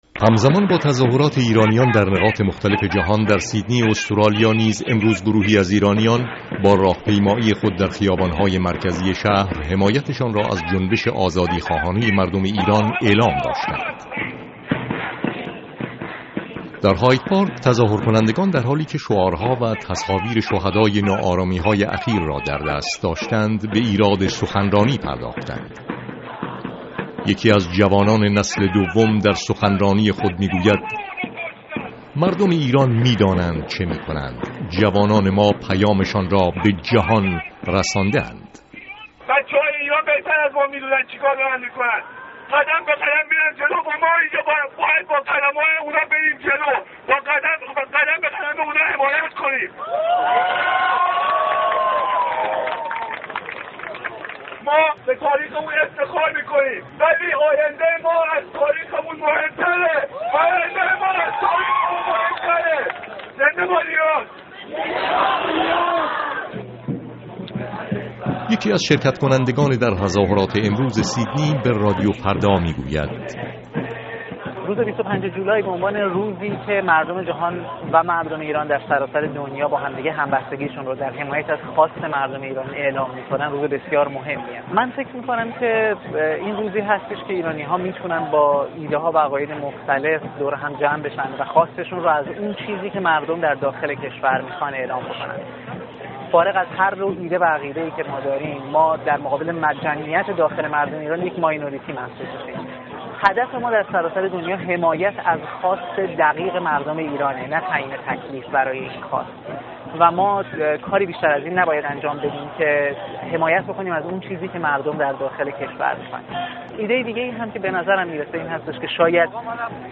گزارش «روز جهانی اقدام» از سیدنی